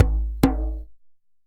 DJEM.HIT16.wav